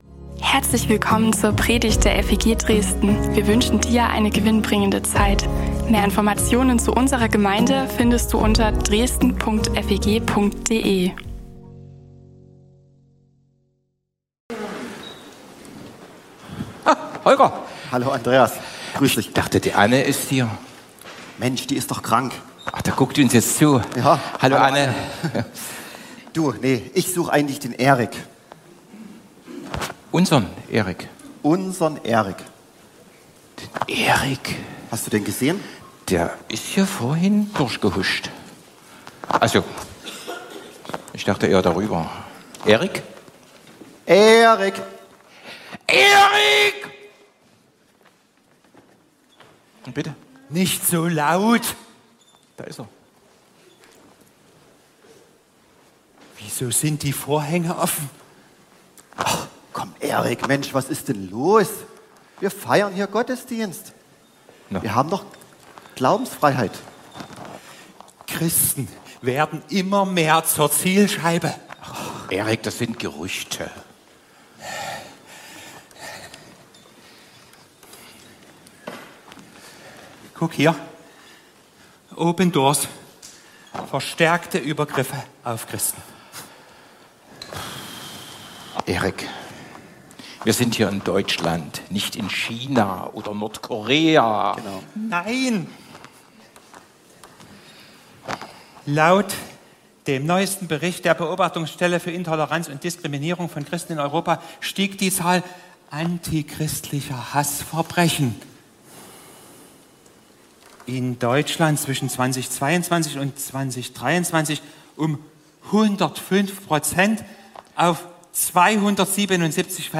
FeG Dresden Predigten und mehr